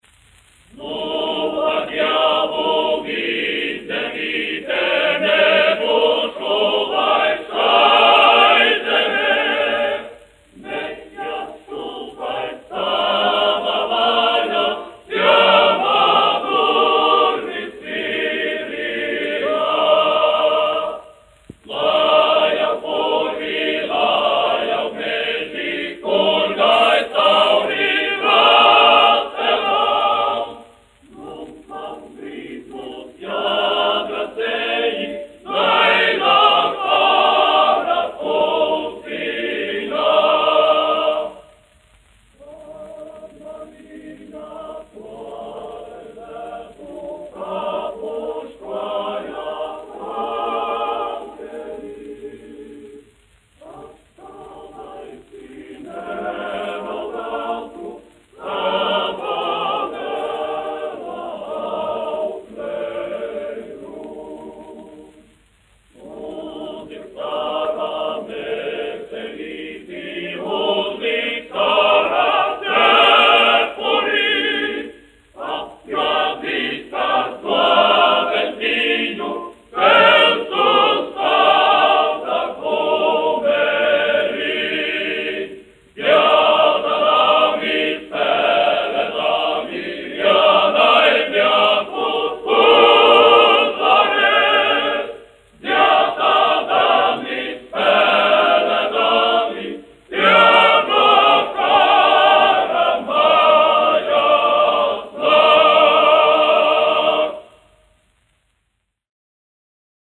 Reitera koris, izpildītājs
1 skpl. : analogs, 78 apgr/min, mono ; 25 cm
Kori (vīru)
Skaņuplate
Latvijas vēsturiskie šellaka skaņuplašu ieraksti (Kolekcija)